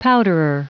Prononciation du mot powderer en anglais (fichier audio)
Prononciation du mot : powderer